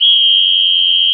r_whist1.mp3